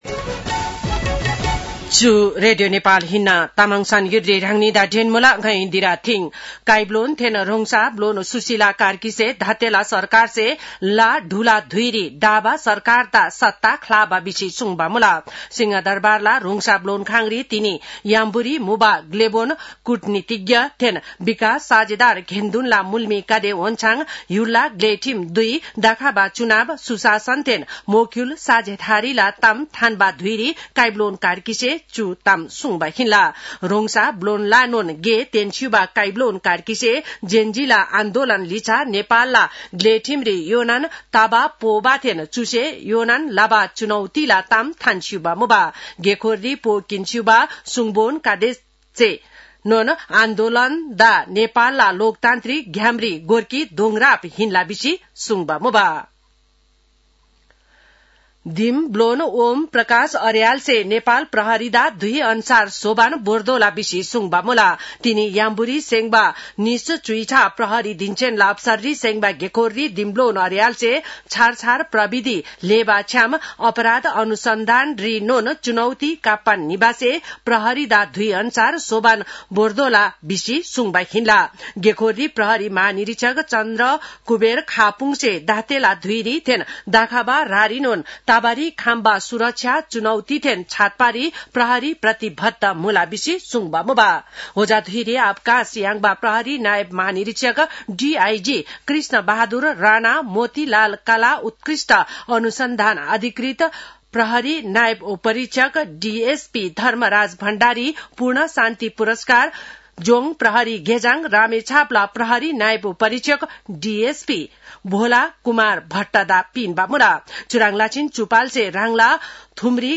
तामाङ भाषाको समाचार : ३१ असोज , २०८२
Tamang-news-6-31.mp3